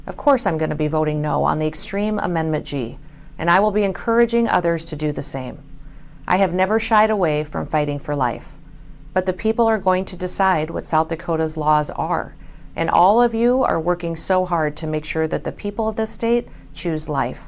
Her announcement came in a video for the Right to Life conventoin held in Yankton.